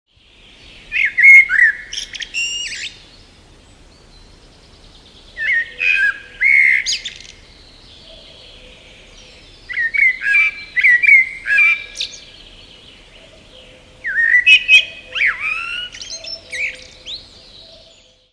Forest birds and their song - blackbird
Blackbird
The blackbird nests in fresh deciduous forests and wetland forests, and sings sitting motionless in the top of the tree. The song is varied, slow and tranquil. It sounds similar to the song of the mistle thrush but the latter only nests in pine forests.